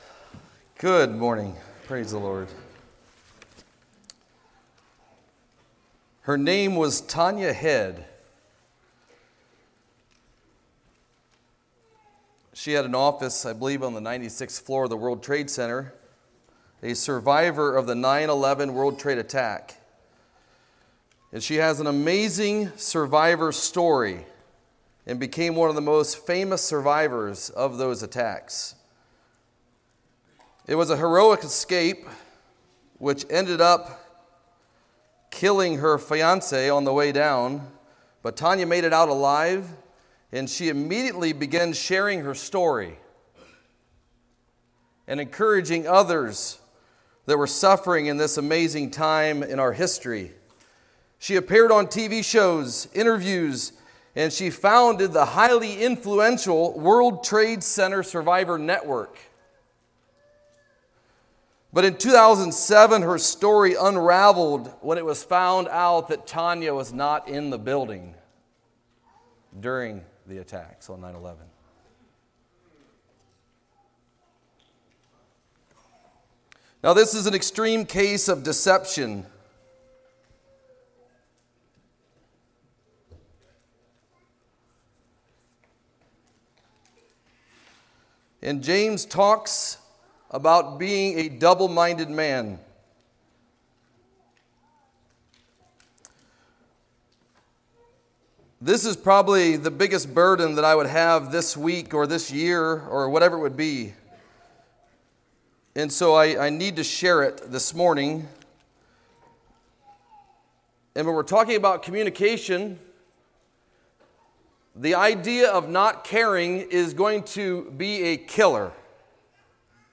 Sermons of 2018 - Blessed Hope Christian Fellowship